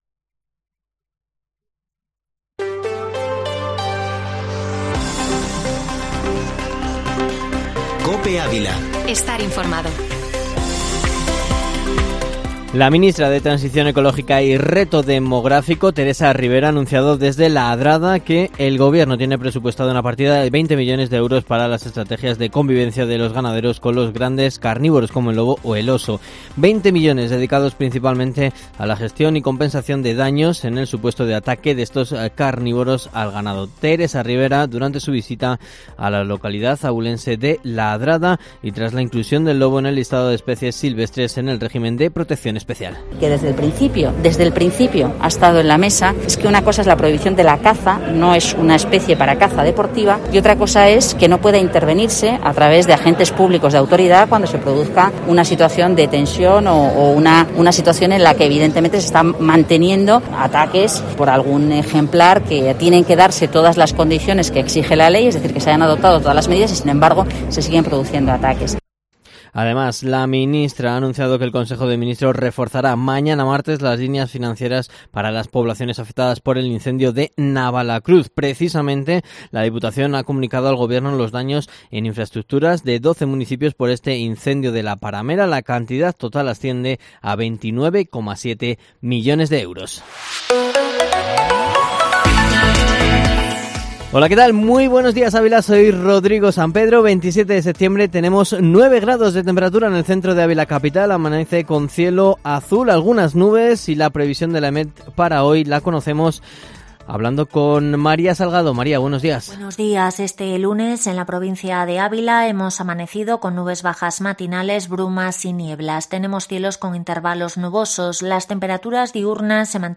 Informativo Matinal Herrera en COPE Ávila -27-sept